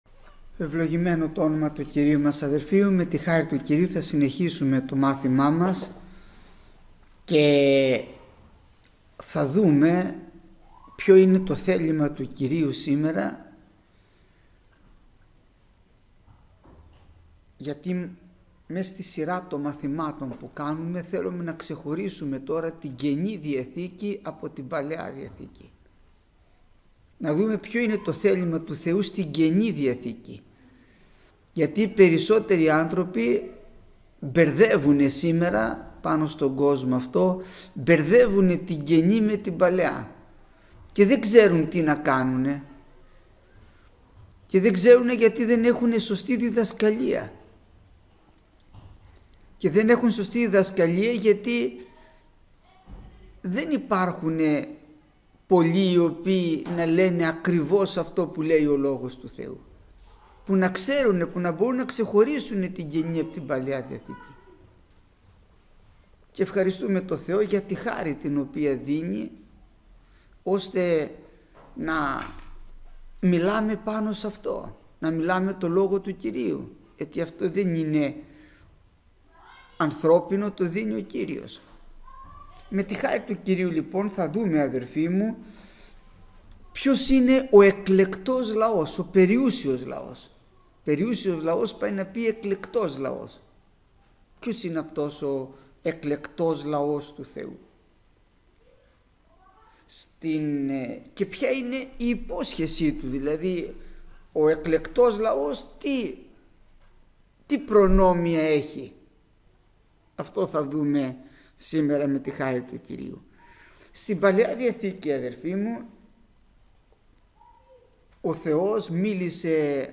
Γεννηθήτω το θέλημά σου – Μάθημα 169ο